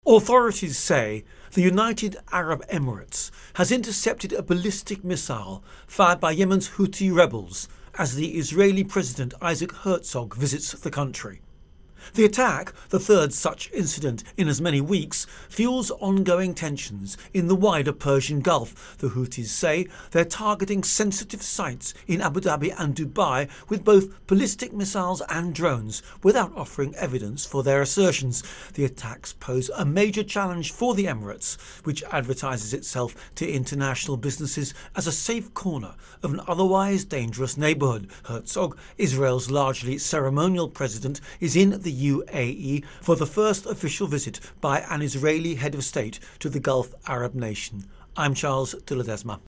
Intro and Voicer on Persian Gulf-Tensions